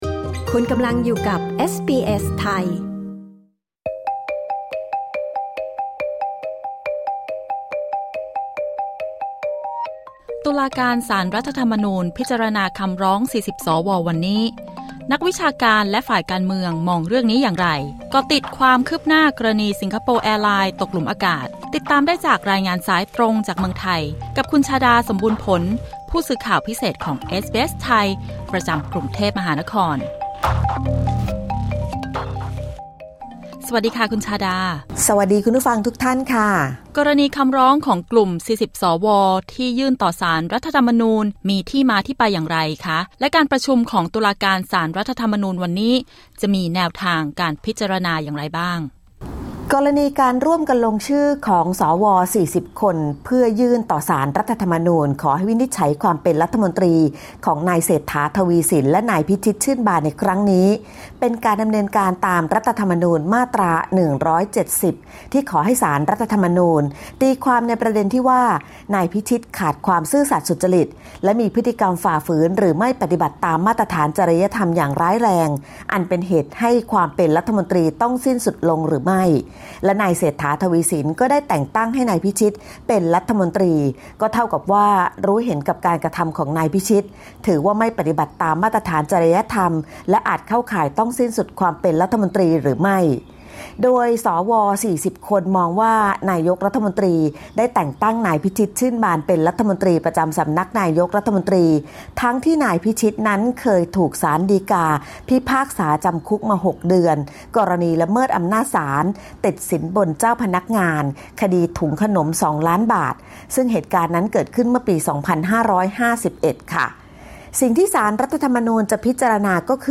ฟังรายงานข่าวด้านบน